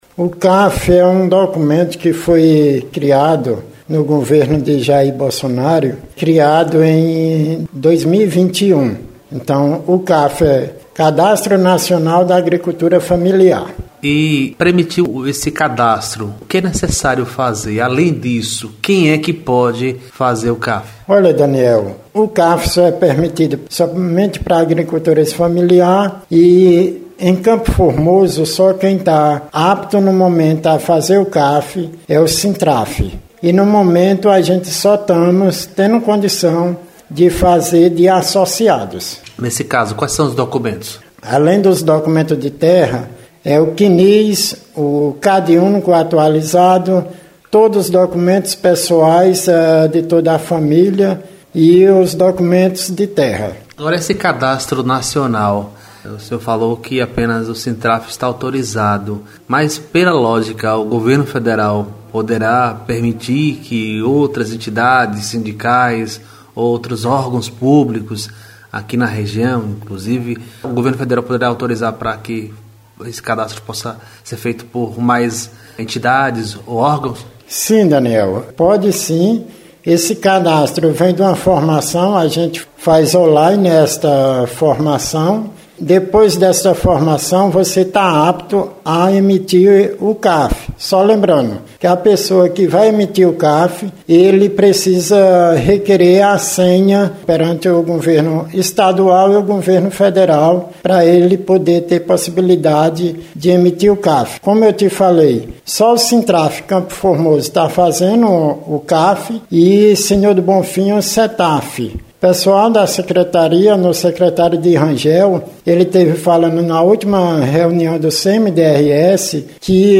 O representante do sindicato de agricultores também explicou a diferença do sistema da DAP para a CAF.